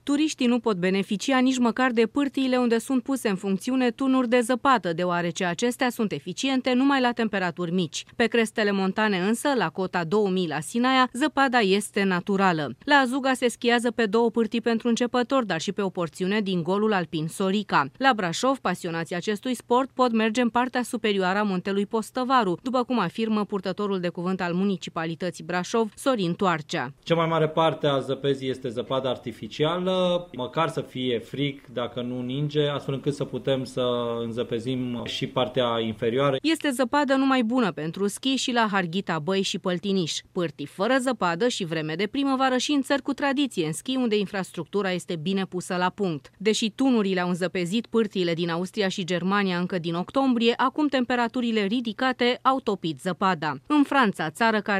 O sinteză